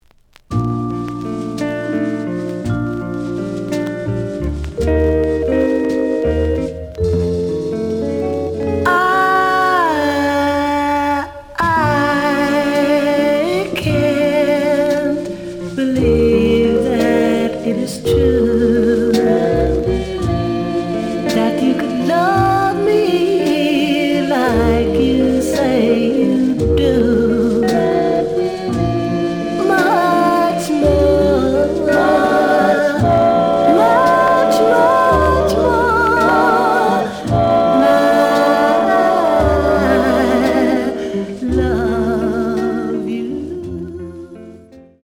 試聴は実際のレコードから録音しています。
The audio sample is recorded from the actual item.
●Genre: Soul, 60's Soul